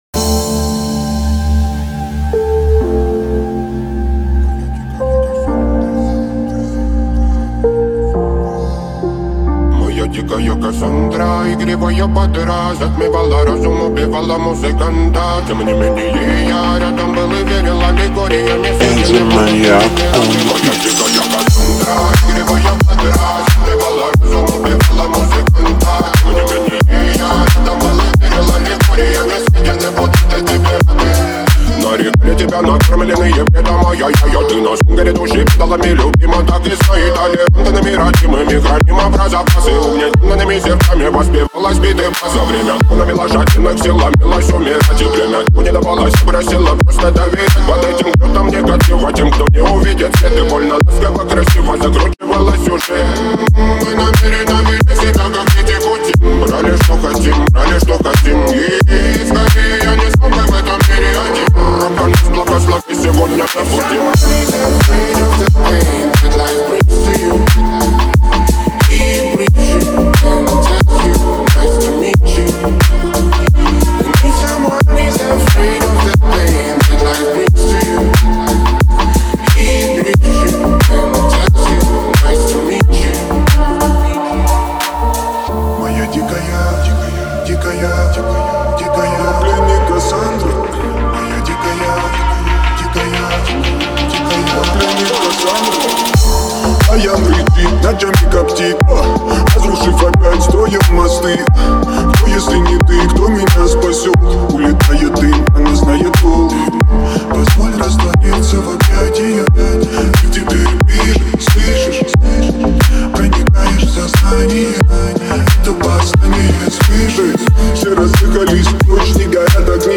пронизан меланхолией и романтикой